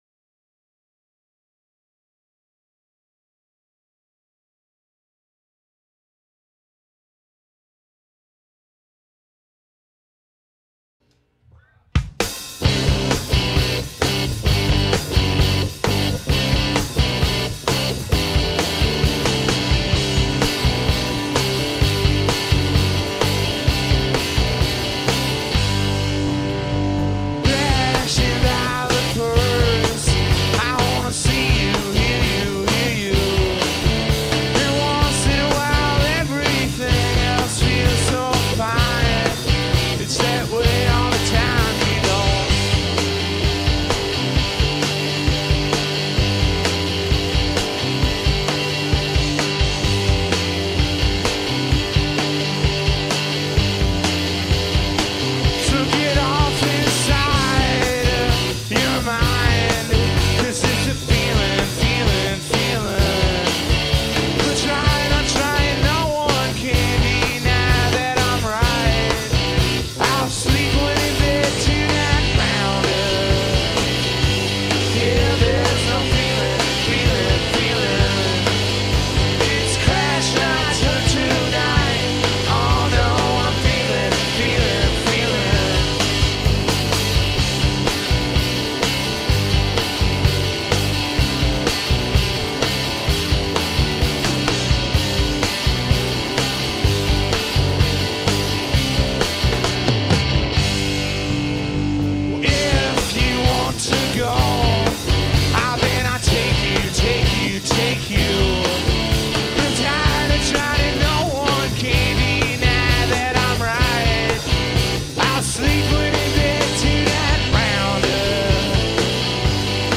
Le trio d’Athens composé des très jeunes
basse
2 sessions studio de l’ancien album